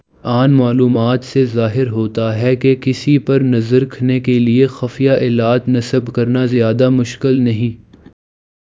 deepfake_detection_dataset_urdu / Spoofed_TTS /Speaker_06 /115.wav